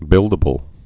(bĭldə-bəl)